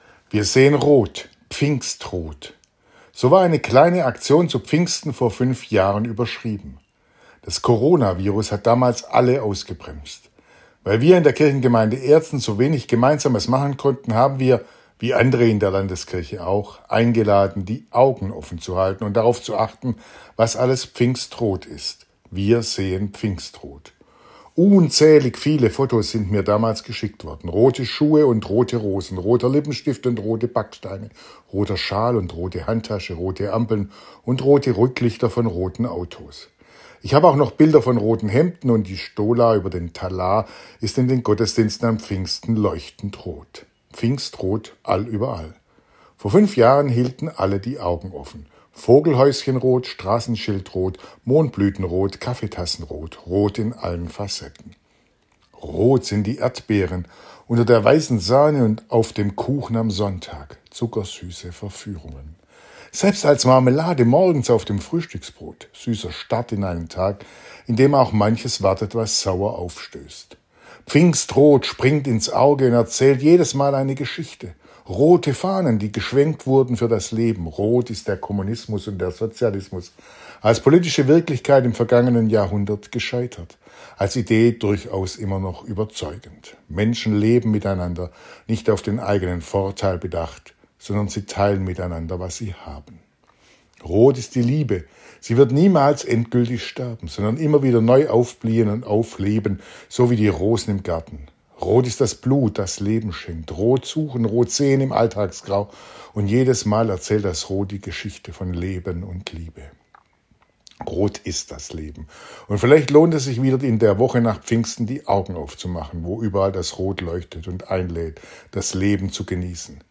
Radioandacht vom 10. Juni